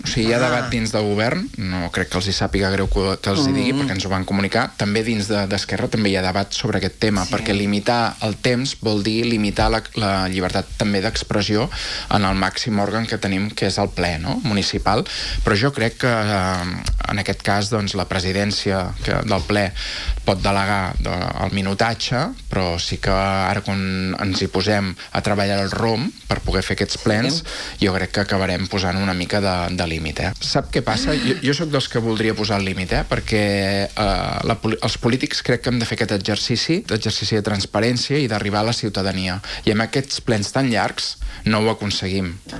Xavier Ponsdomènech, regidor i portaveu d’ERC, que aquest dilluns ha passat per l’espai de l’entrevista, ha defensat la creació d’un pla d’usos per regular els tipus d’establiments al centre.